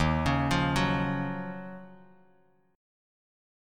Ebm9 chord